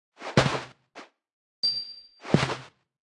Media:Sfx_Anim_Ultra_Trader.wav 动作音效 anim 在广场点击初级、经典、高手和顶尖形态或者查看其技能时触发动作的音效
Sfx_Anim_Super_Trader.wav